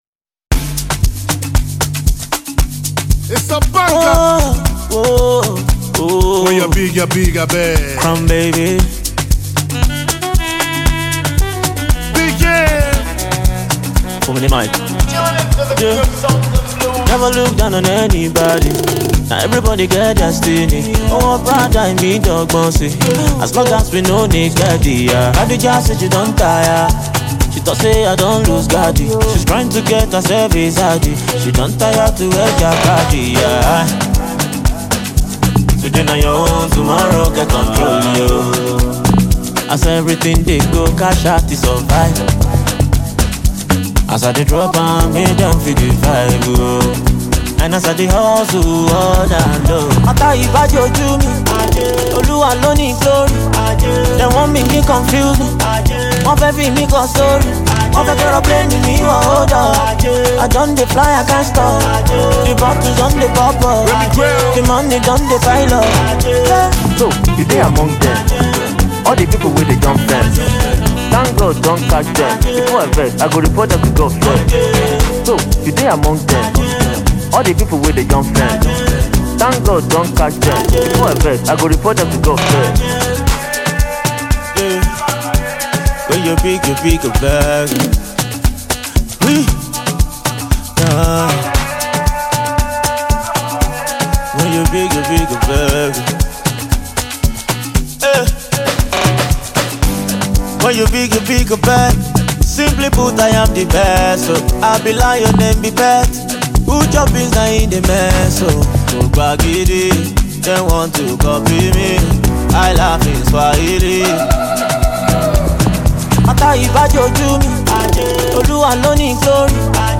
A club number that will definitely have you dancing